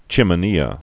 (chĭmə-nēə)